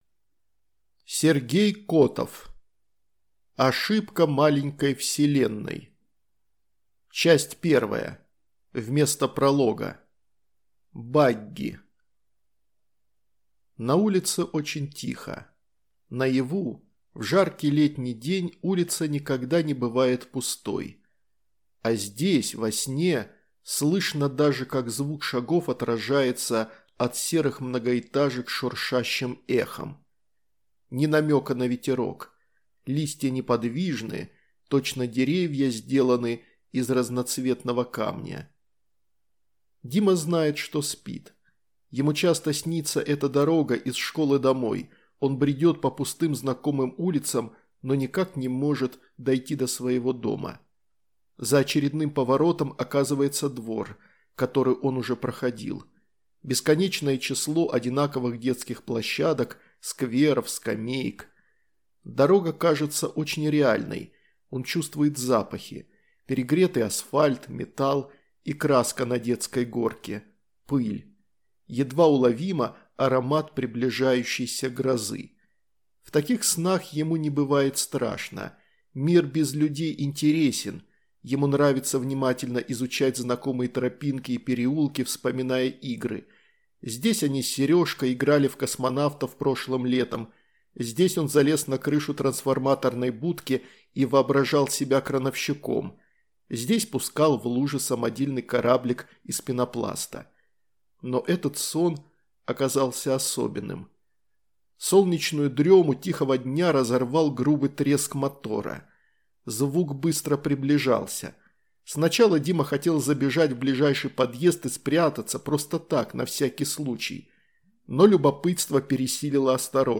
Аудиокнига Ошибка маленькой вселенной | Библиотека аудиокниг